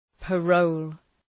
Shkrimi fonetik {pə’rəʋl}